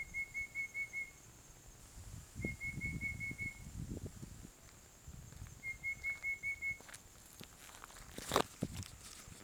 因為有版友私下跟我說山紅頭不太容易遇到 有可能是我誤認 所以錄起來確定一下 比對之下 的確很像 我之前認為是救護車聲音 (仔細聽 越聽越不像救護車~"~)
第一個是山紅頭；山紅頭非常容易遇到